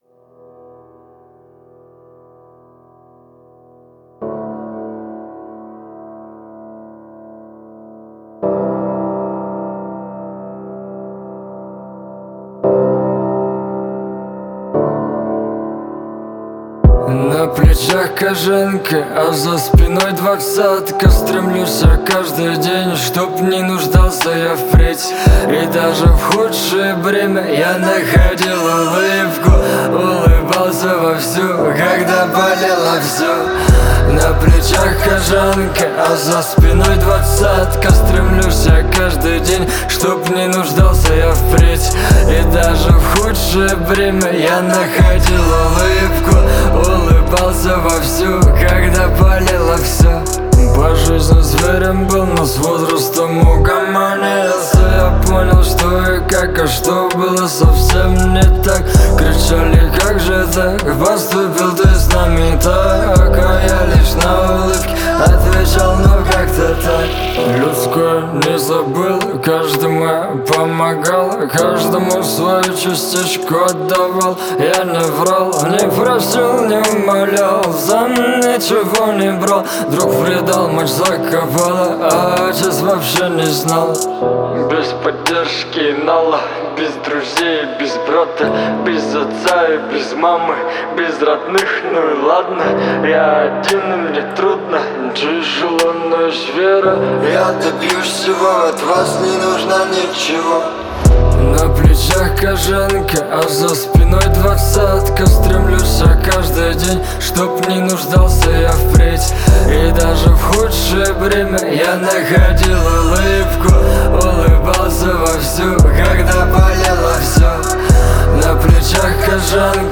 это песня в жанре поп с элементами фолка